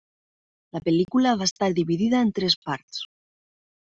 Pronounced as (IPA) /ˈpars/